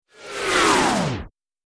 转场-影视效果-图秀网